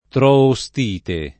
[ tro-o S t & te ]